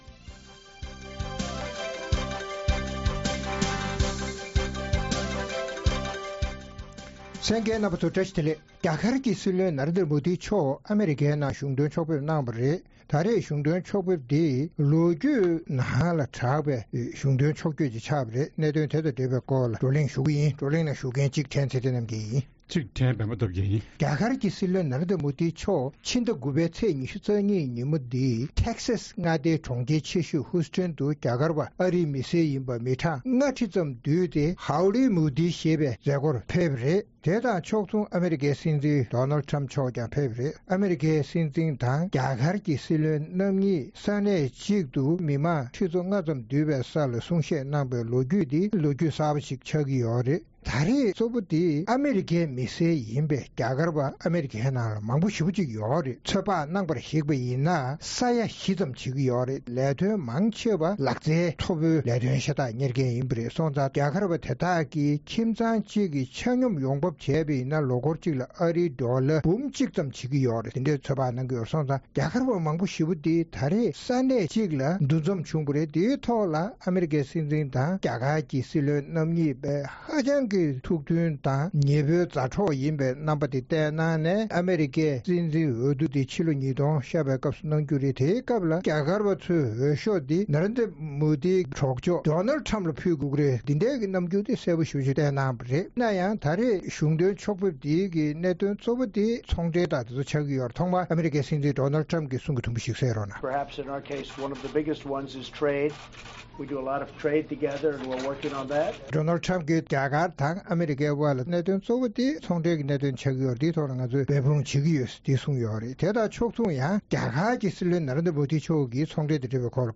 རྩོམ་སྒྲིག་པའི་གླེང་སྟེགས་ཞེས་པའི་ལེ་ཚན་ནང་། རྒྱ་གར་གྱི་སྲིད་བློན་ན་རེན་དྲ་མོ་དི་Narendra Modi མཆོག་ཨ་རིའི་ནང་གཞུང་དོན་ཕྱོགས་ཕེབས་གནང་བའི་གྲུབ་འབྲས་ཇི་བྱུང་སོགས་ཀྱི་སྐོར་རྩོམ་སྒྲིག་འགན་འཛིན་རྣམ་པས་བགྲོ་གླེང་གནང་བ་ཞིག་གསན་རོགས་གནང་།